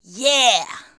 YEAH 2.wav